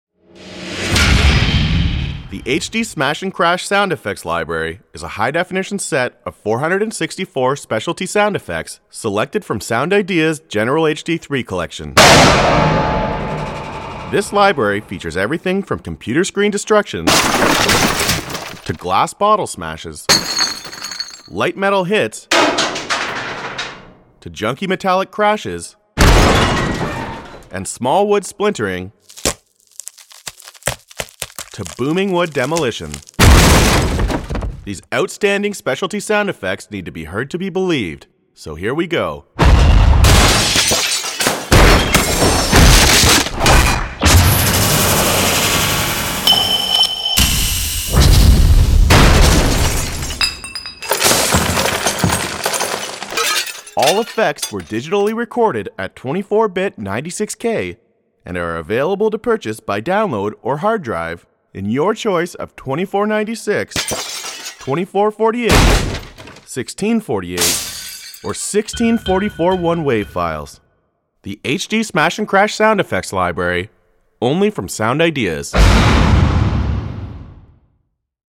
From Computer Screen Destruction to Glass Bottle Smashes – Light Metal Hits to Junky Metallic Crashes – Small Wood Splintering to Booming Wood Demolition – these outstanding specialty sound effects need to be heard to be believed.
Sound Effects include, Glass, Metal & Wood:
• Digitally recorded audio files in stereo at 24 bit / 96 kHz
smash and crash REV 2.mp3